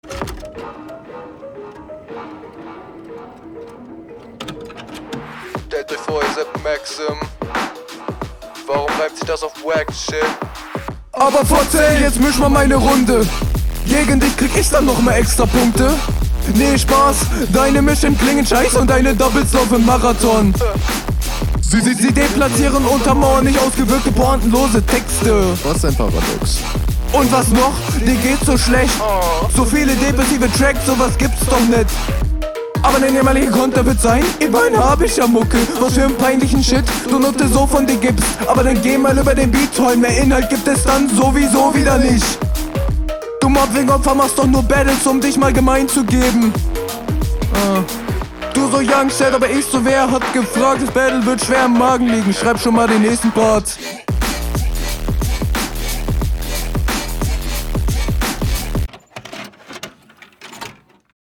Ich mag den Beat.